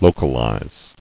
[lo·cal·ize]